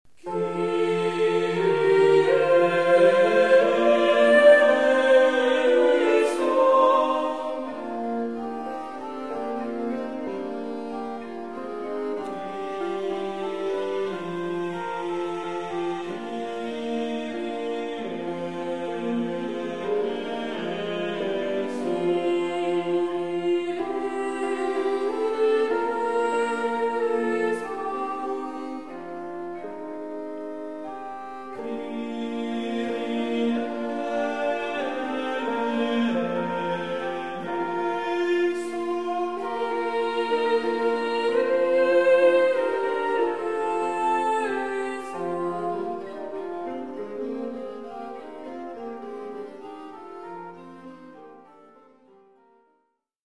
Ansambel Cantica Symphonia (Itaalia)
sopran
tenor
bariton
portatiivorel
flööt, fiidel